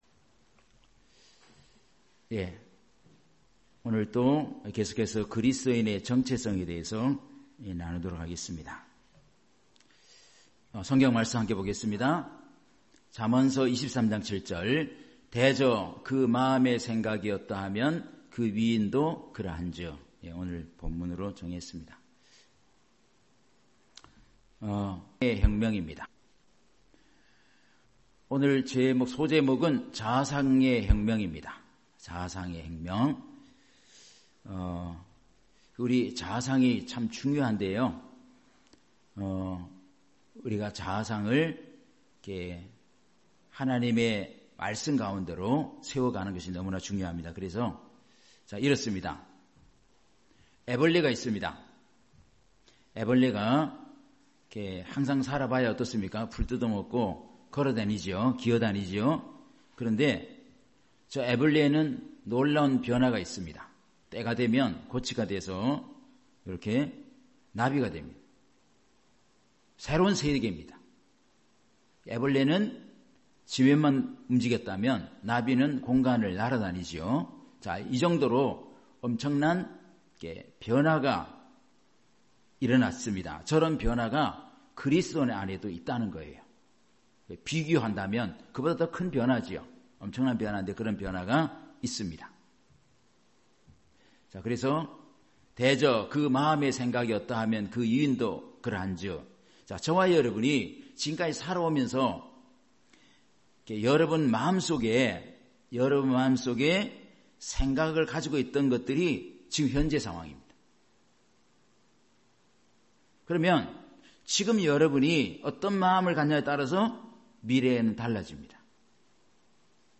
주일 오전 말씀 - 그리스도인의 정체성(8)